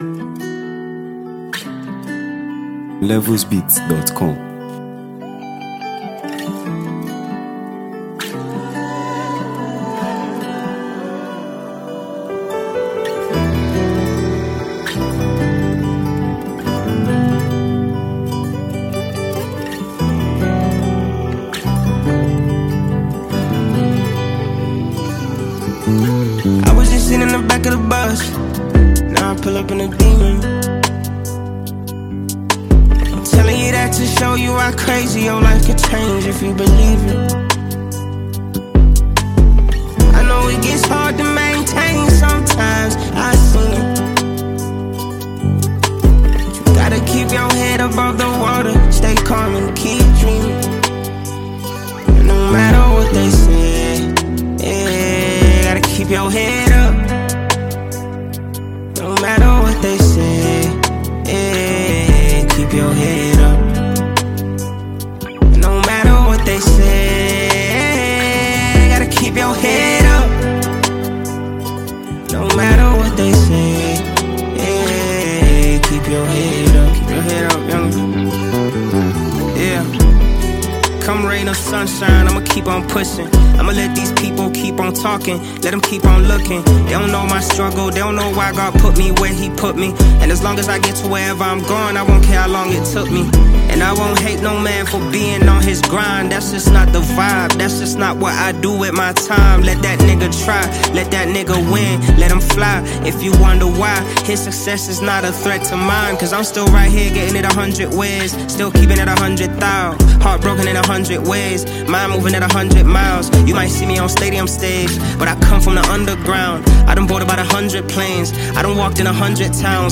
South African hip-hop heavyweight and lyrical genius